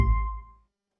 victory.wav